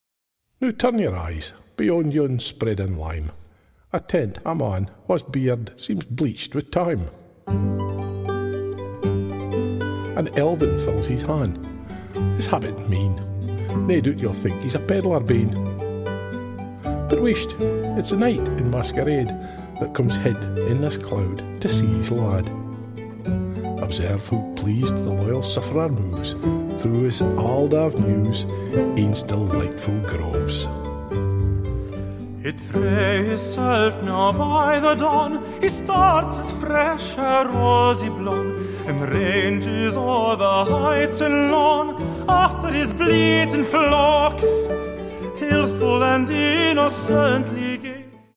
• Genres: Early Music, Opera